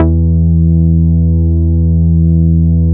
DAF BASS 6.wav